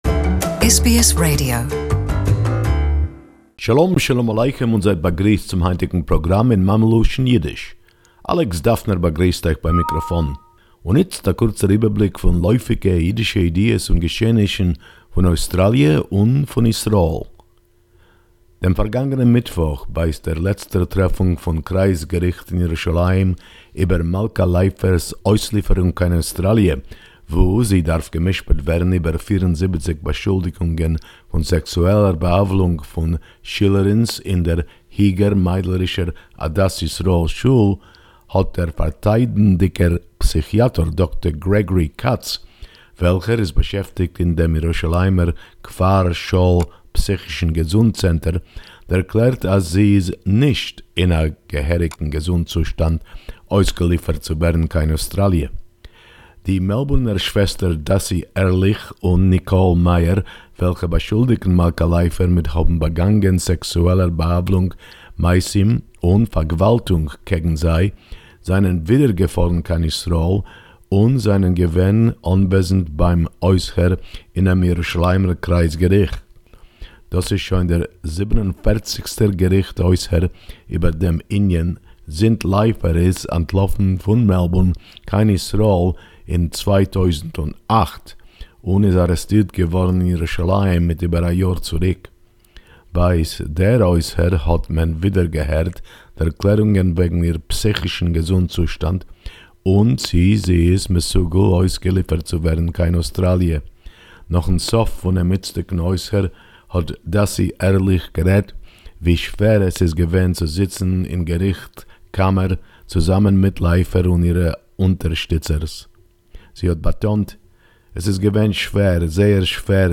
Yiddish report, latest news